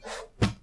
Book Close.ogg